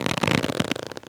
foley_leather_stretch_couch_chair_11.wav